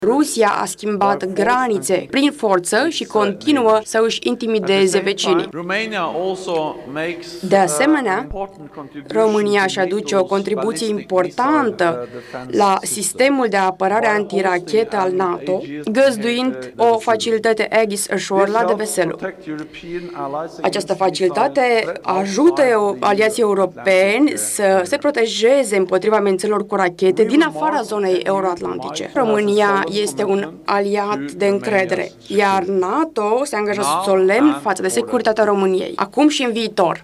Secretarul general al NATO a dat asigurări la Palatul Cotroceni că organizația Nord-Atlantică va asigura acum și în viitor securitatea României.
Oficialul a mai spus că acest scut antirachetă este menit să protejeze țările din Europa de amenințări cu rachete din afara spațiului euro-atlantic: